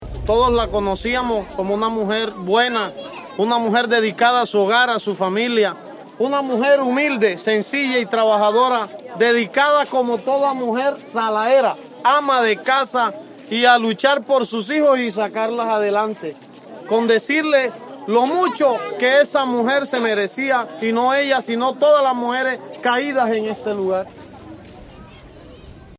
Un recorrido por la memoria que evoca el dolor, la resistencia, la esperanza y las reivindicaciones de las víctimas que ahora alzan su voz frente a la historia que las silenció. Su memoria se narra a partir de sonidos propios del folclore montemariano, como el vallenato y las décimas, y también desde la poesía y los testimonios que interpretan los relatos de los victimarios.